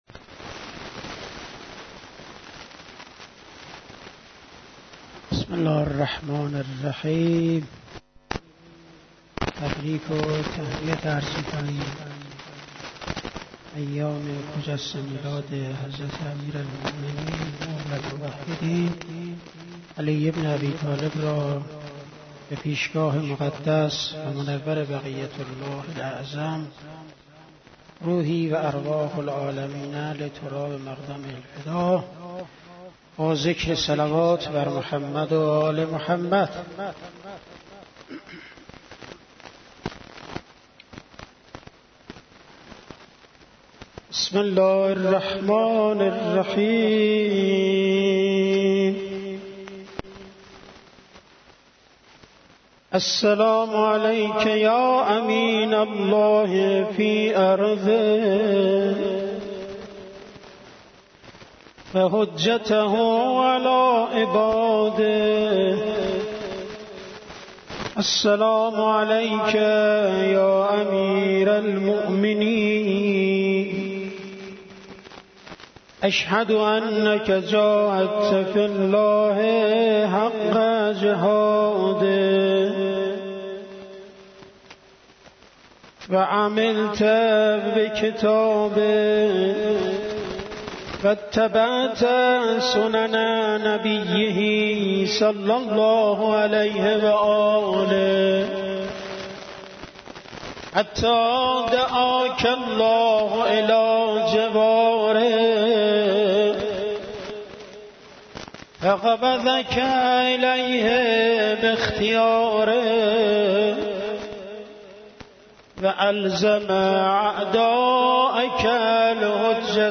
قرائت دعای امین الله و زیارت حضرت امیر